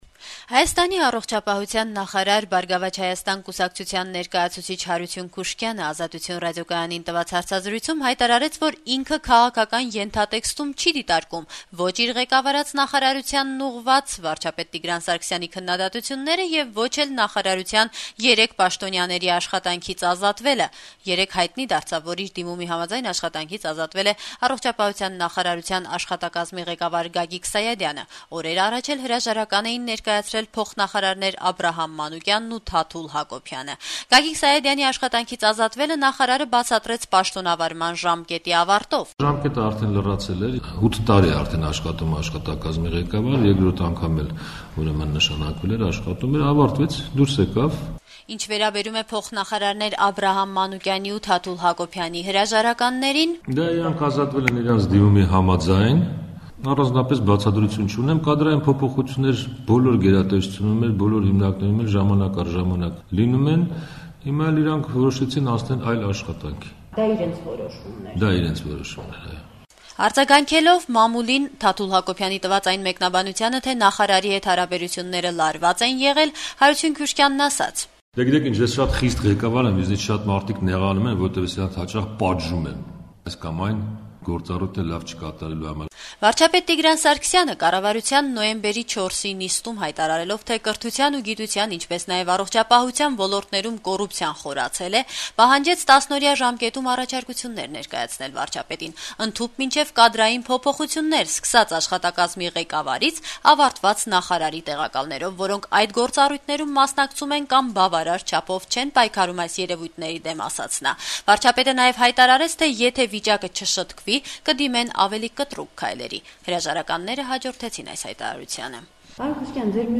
Բացառիկ հարցազրույց Հարություն Քուշկյանի հետ